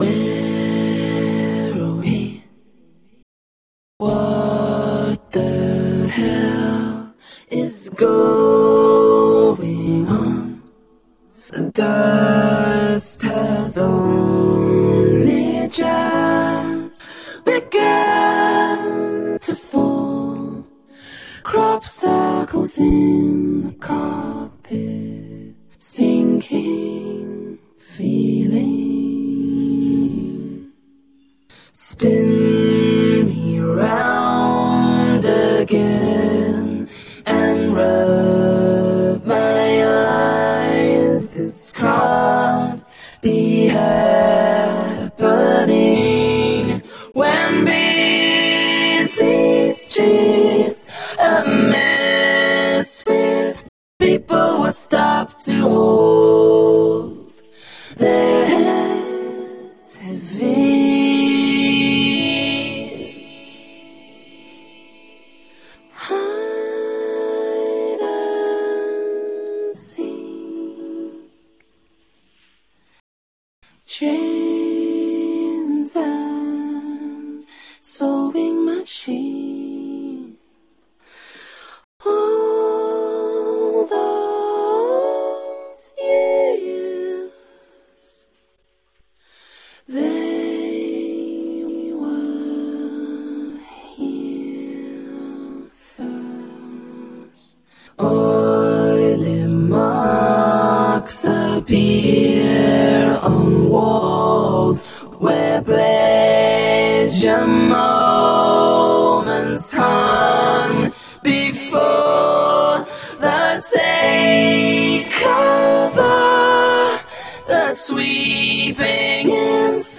The BPM is 120.